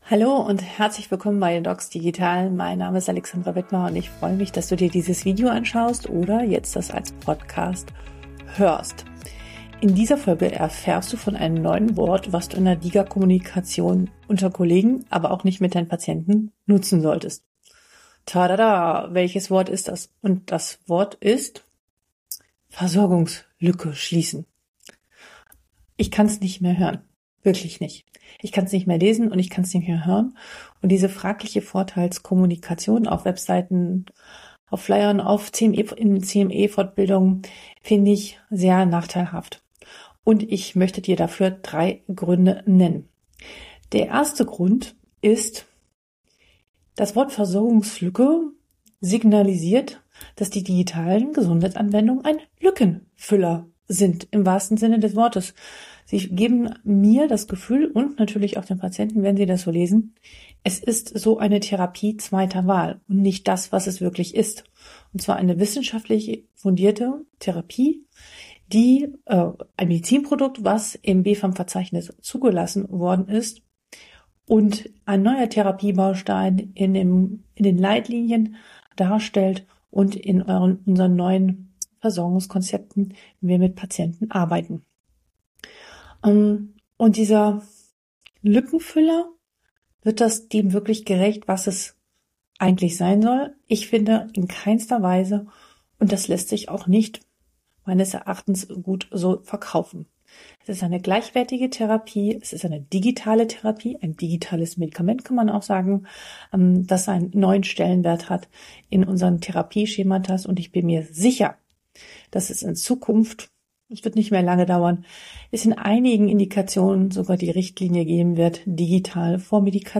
Eine Solofolge und meine Erkenntnisse aus der DiGA Praxis